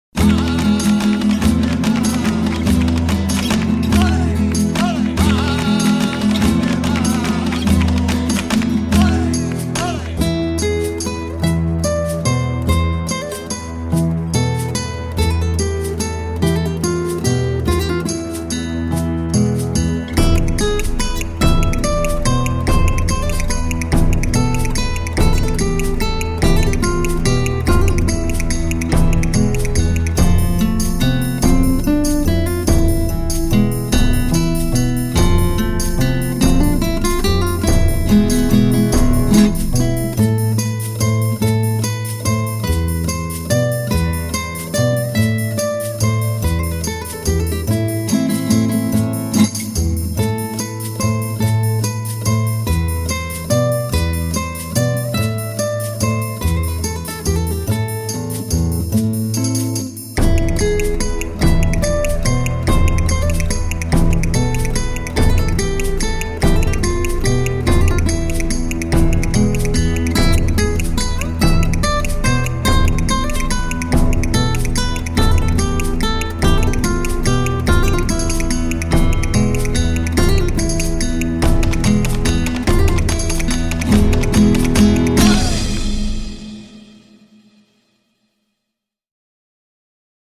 Solo gitaar